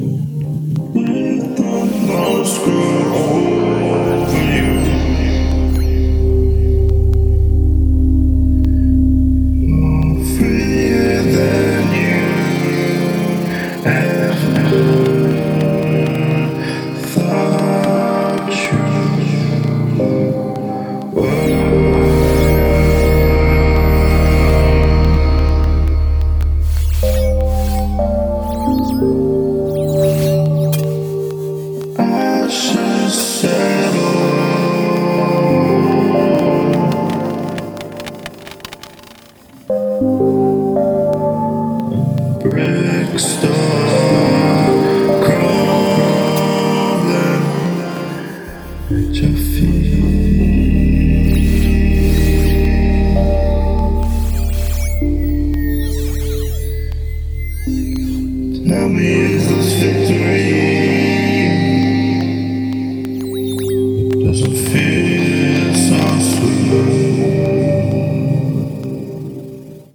Genre Ambient , Experimental